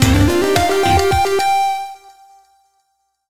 retro__musical_stinger_02.wav